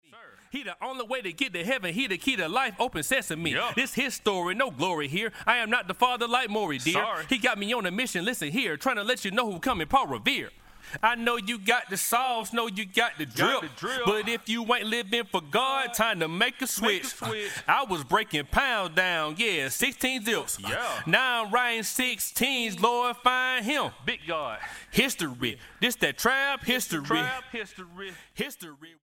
STYLE: Hip-Hop
Acapella